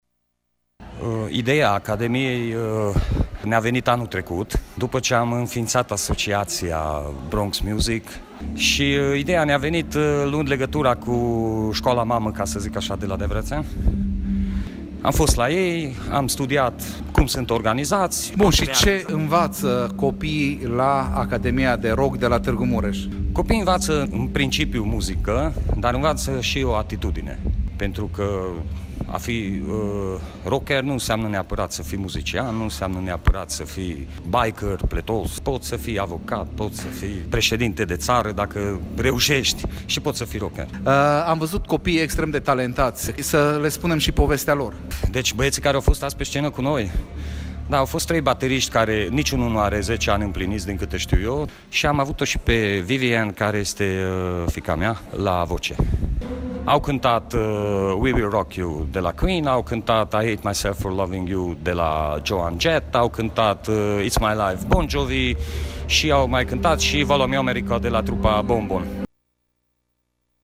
Aflăm detalii din interviul